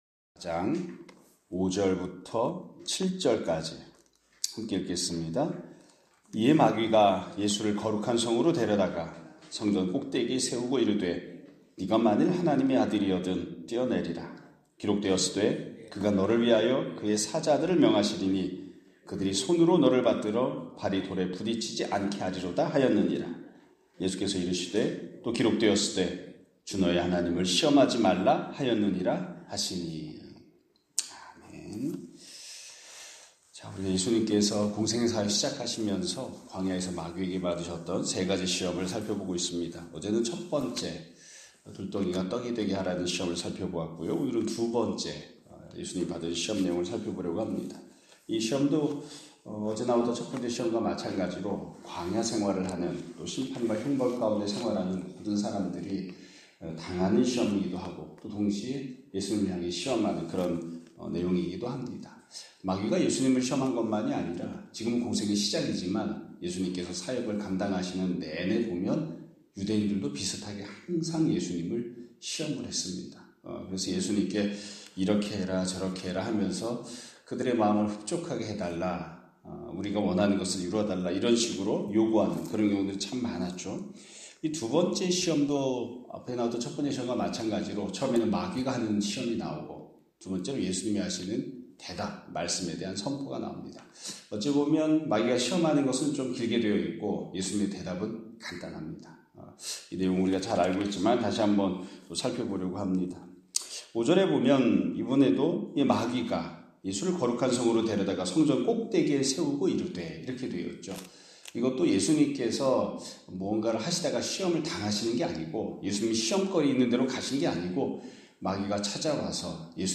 2025년 4월 25일(금요일) <아침예배> 설교입니다.